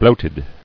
[bloat·ed]